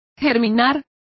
Complete with pronunciation of the translation of sprouting.